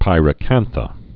(pīrə-kănthə)